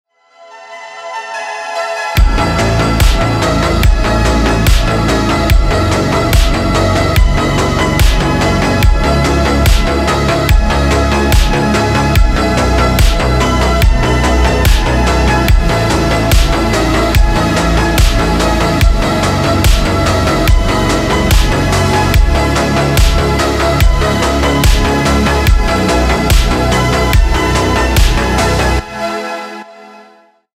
• Качество: 320, Stereo
электронная музыка
synthwave
Retrowave